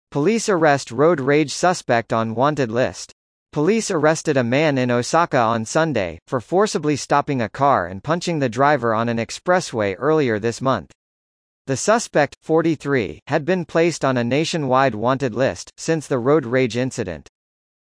（区切りなしのナレーションです。）